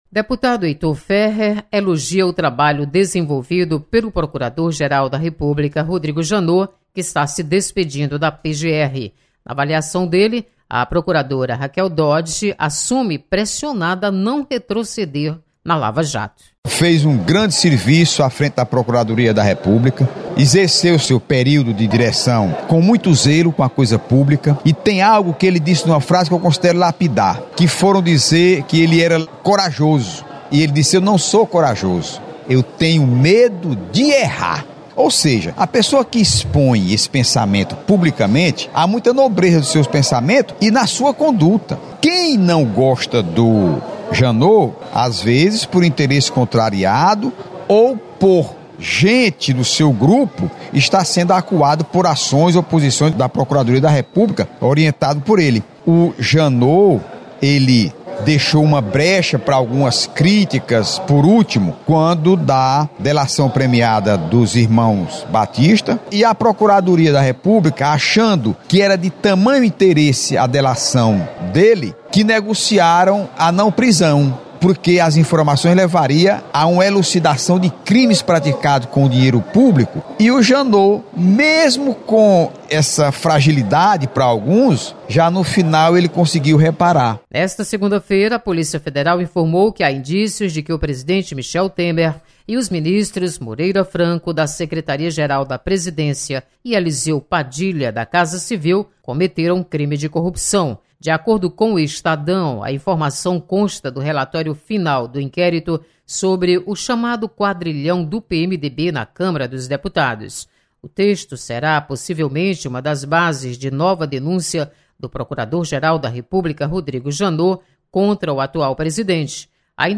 Deputado Heitor Férrer comenta sobre o trabalho do Procurador Geral da República, Rodrigo Janot.